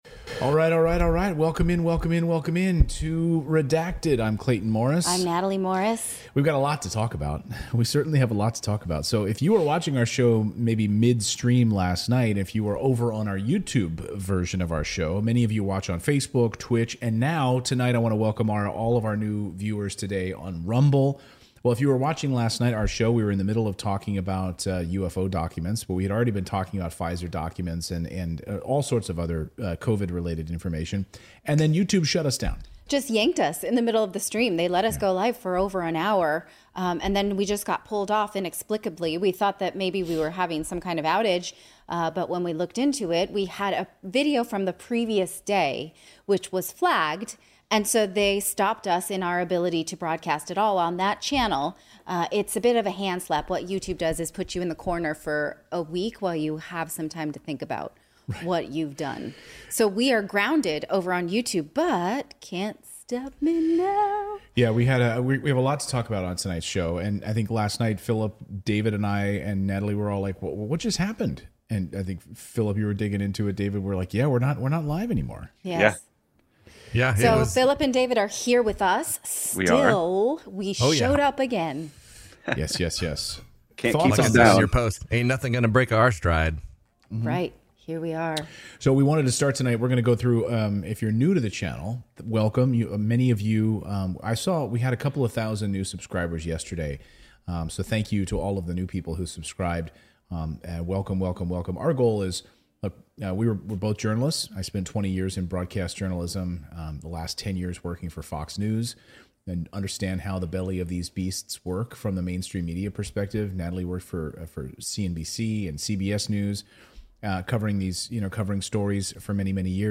This is a VERY important conversation!